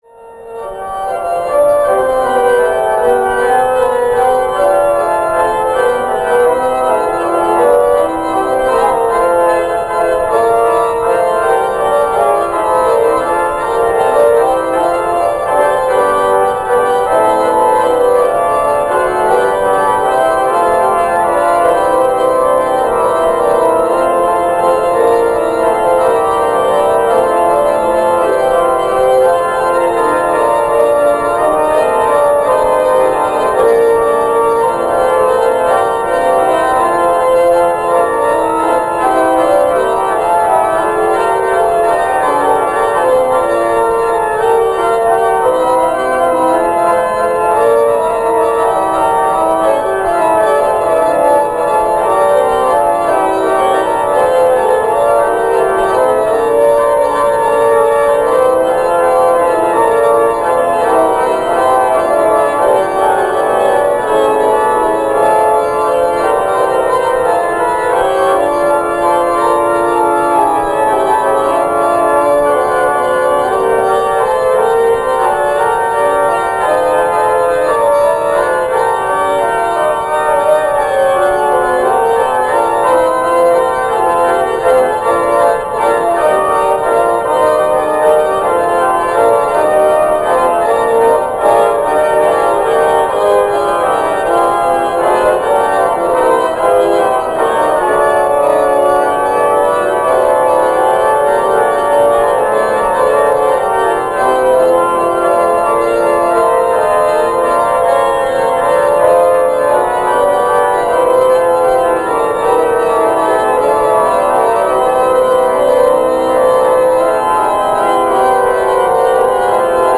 Organo della Basilica di San Pietro in Roma.
N.D.R.  La registrazione è stata eseguita con mezzi di fortuna, ci auguriamo di rivederlo, risentirlo e registrarlo, presto a Buja.